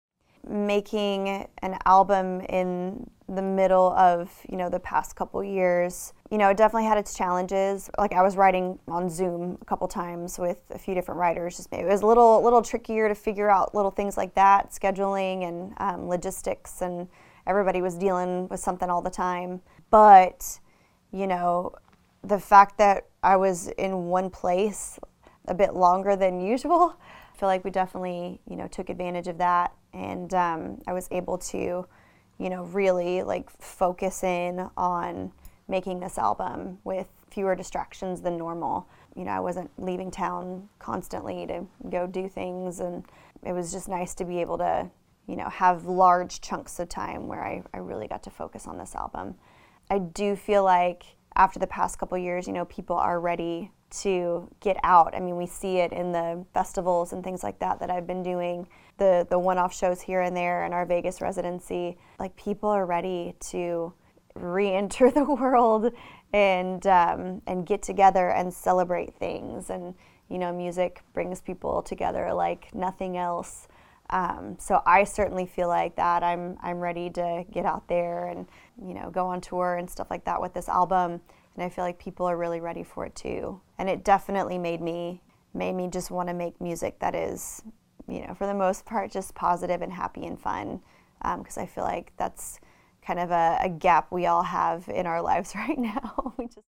Audio / Carrie Underwood talks about making her new album, Denim & Rhinestones, during the last couple of years.